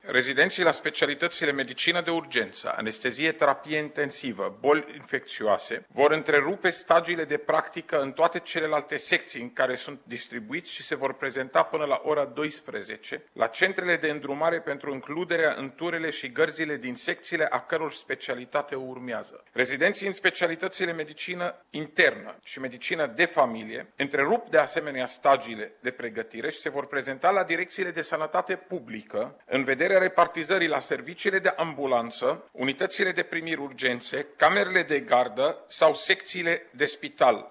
Secretarul de stat în Ministerul de Interne, Raed Arafat, a anunţat că s-a dispus redistribuirea medicilor rezidenţi, în sprijinul personalului medical din spitale şi din serviciile de urgenţă: